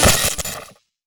etfx_explosion_lightning.wav